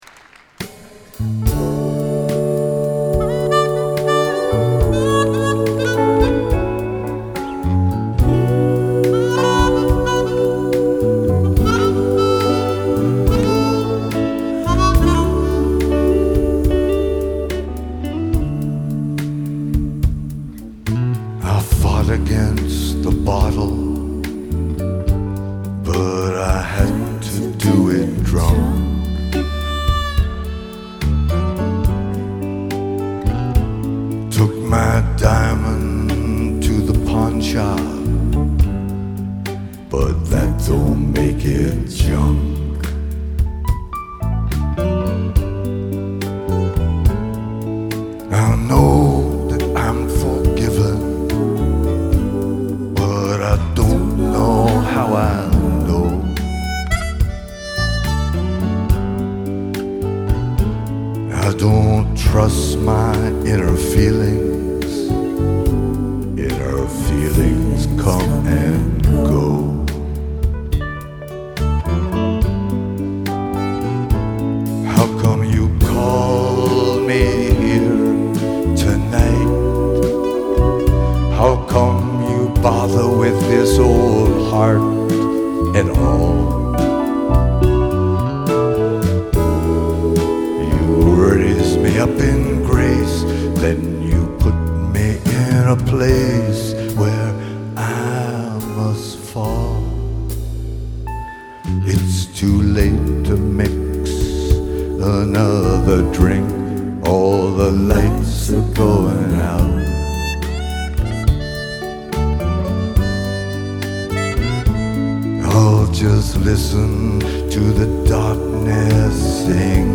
Live Nov 13